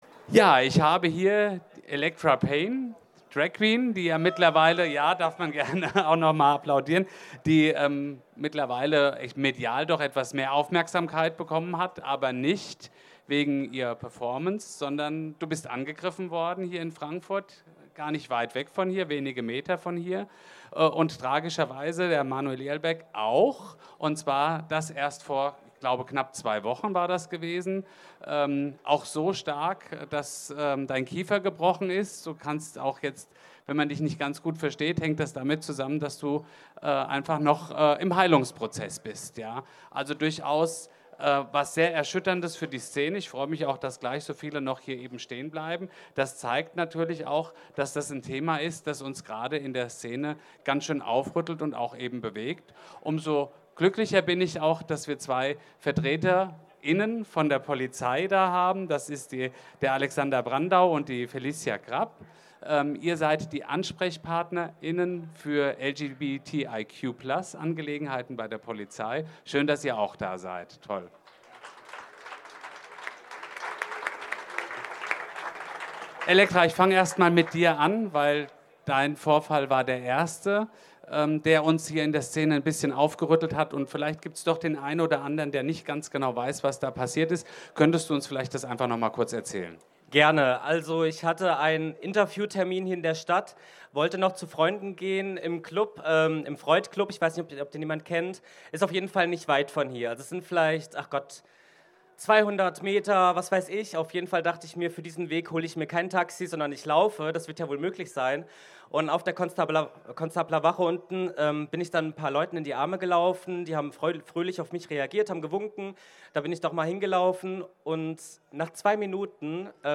Mitschnitt einer Diskussion auf der Kulturbühne vom CSD 2022